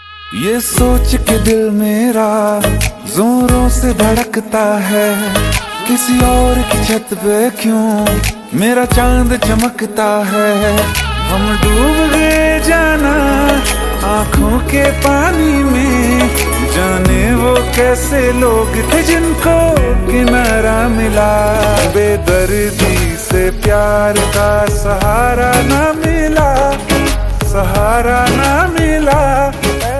sad romantic ringtone